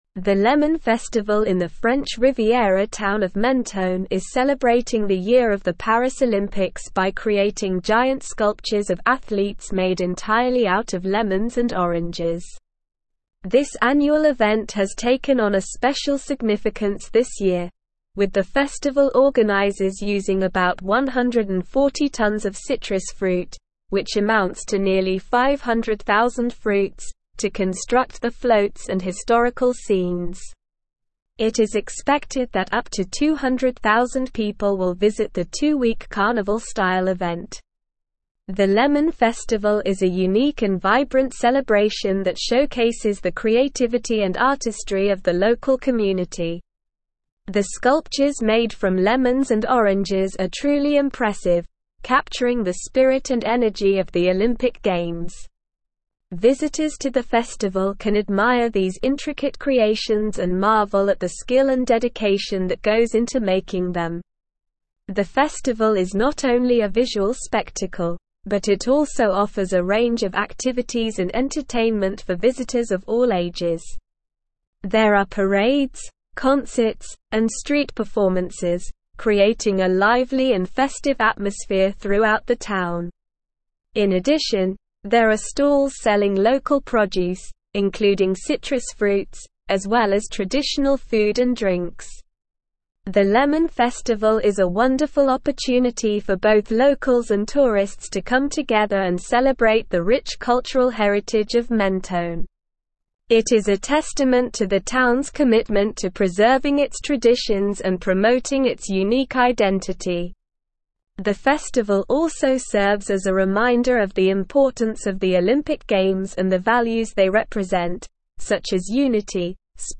Slow
English-Newsroom-Advanced-SLOW-Reading-Lemon-Festival-in-Menton-Celebrates-Paris-Olympics.mp3